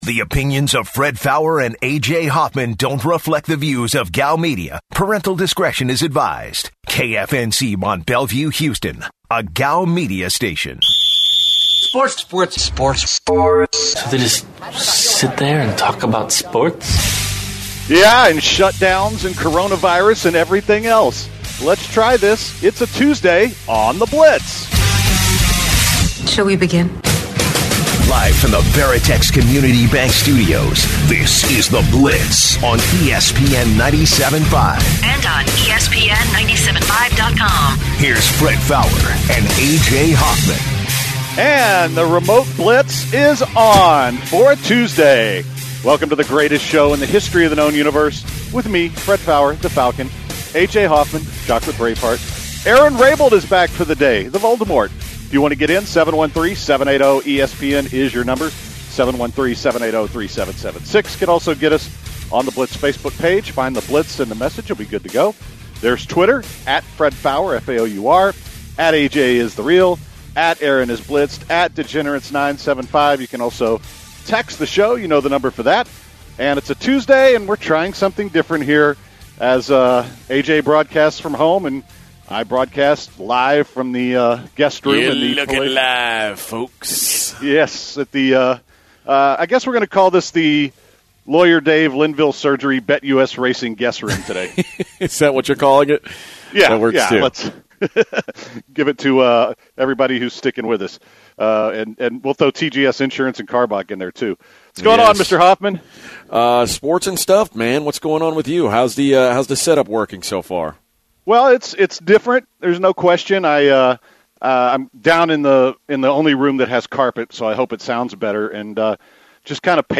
broadcast from their respected houses to help prevent the spread of Coronavirus